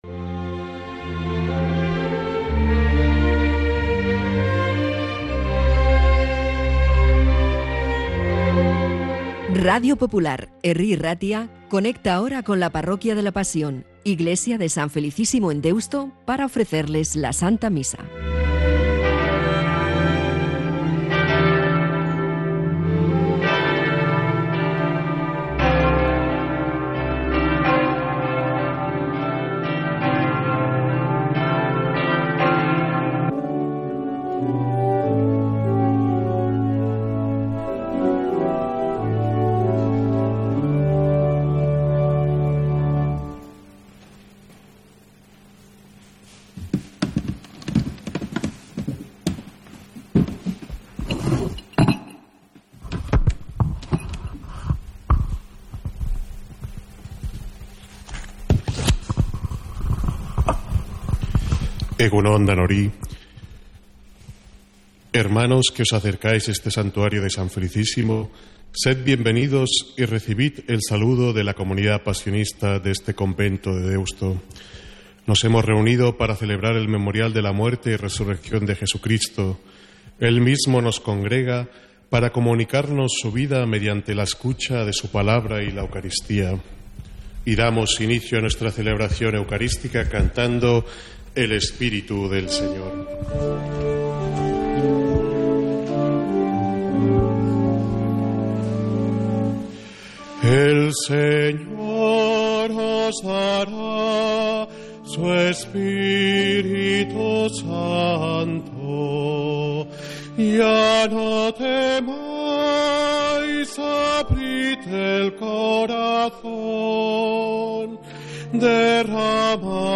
Santa Misa desde San Felicísimo en Deusto, domingo 26 de enero